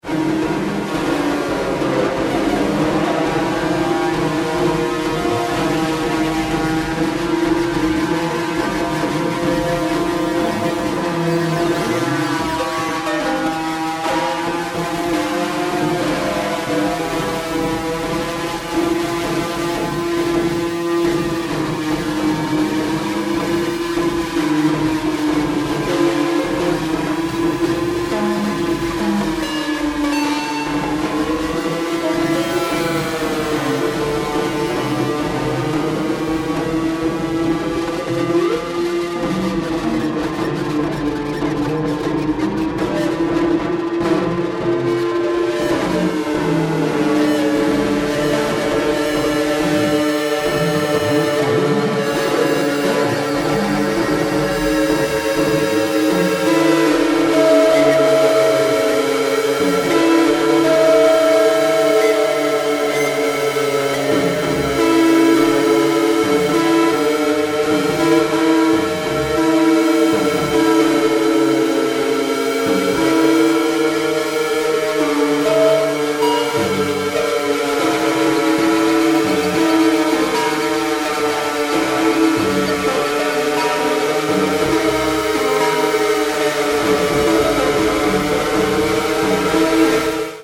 Solo guitar improvisations, variously layered
Gibson ES-335 guitar
I also used an e-bow and A/DA Flanger.